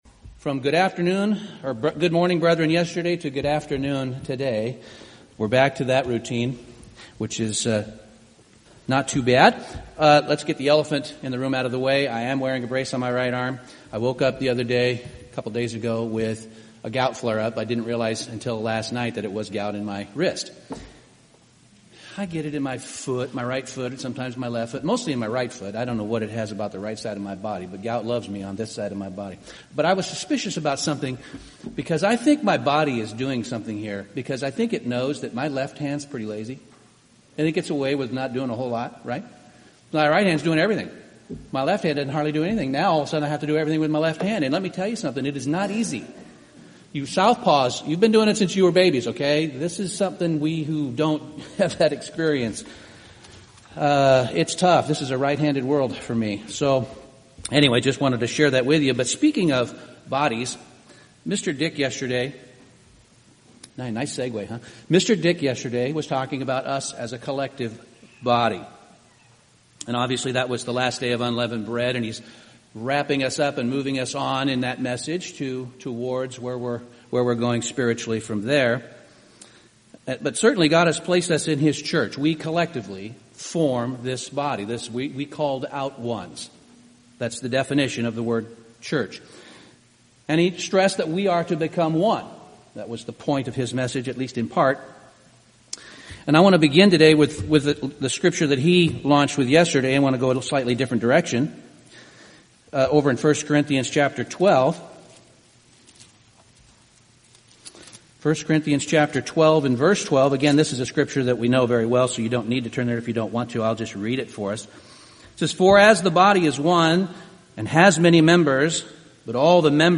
This sermon explores what it means to bear with one another as members of the body of Christ.